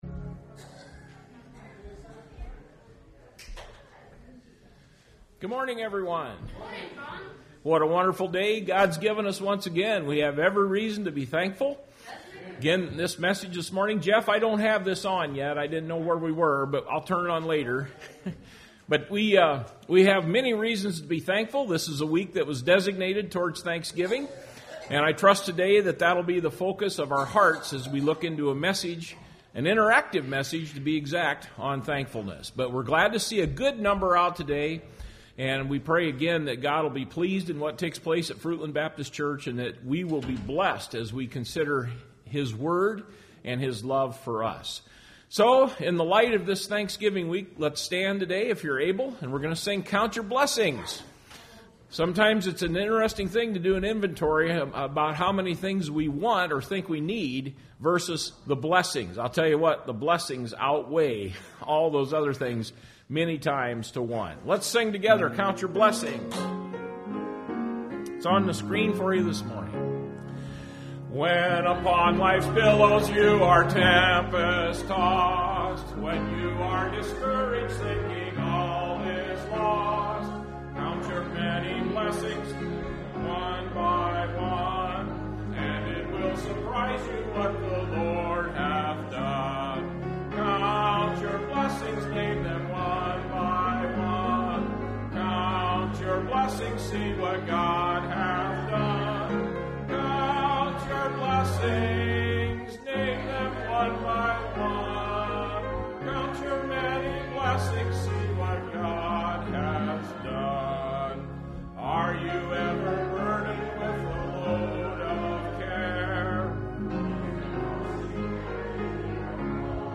Service Type: Sunday Morning Service Topics: Christian Living , Thankfulness